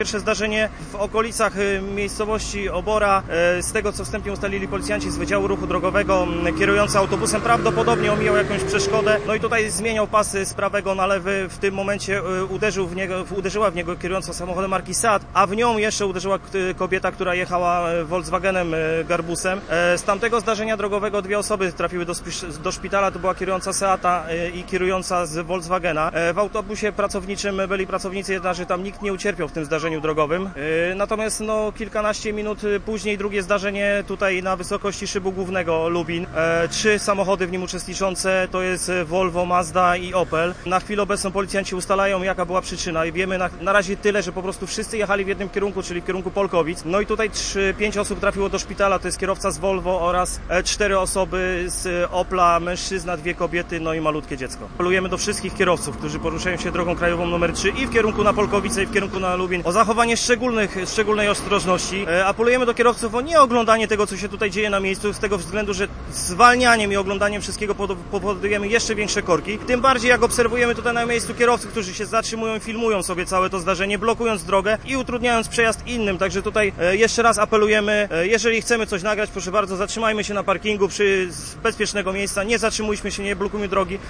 Relacja z miejsca wypadków